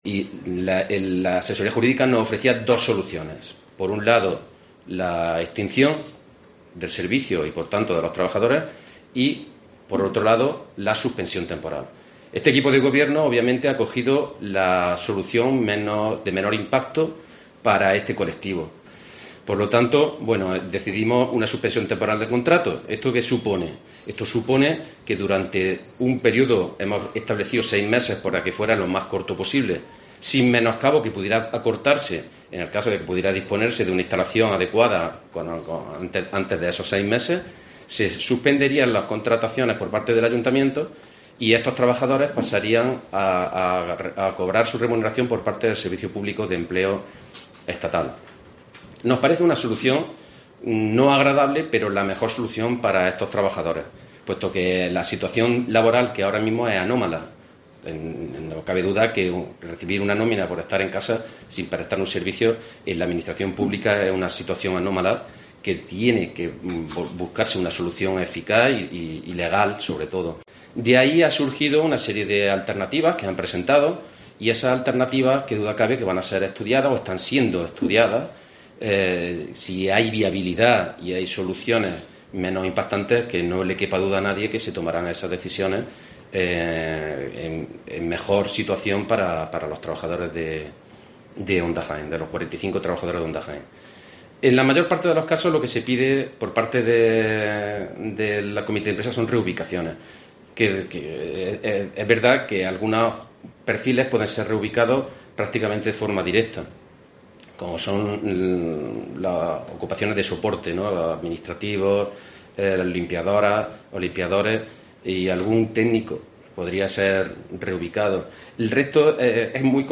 Carlos Alberca, edil de personal, anuncia el ERTE